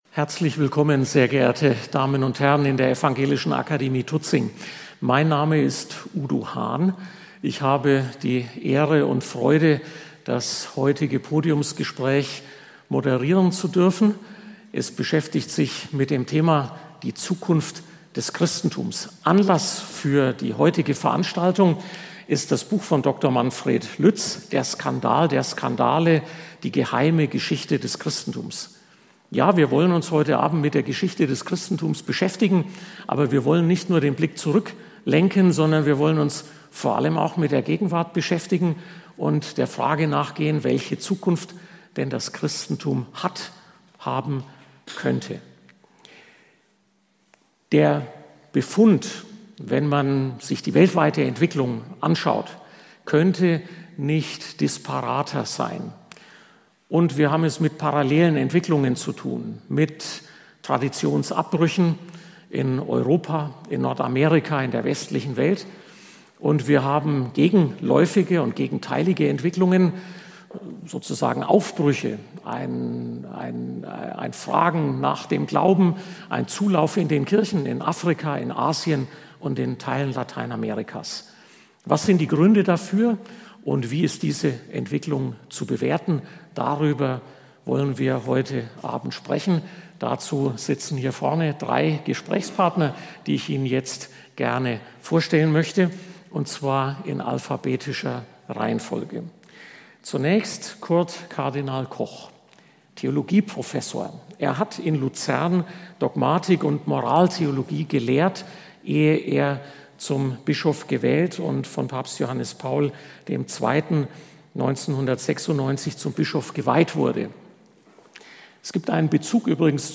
Unter diesem Link gelangen Sie zurück zum Bericht über die Podiumsdiskussion.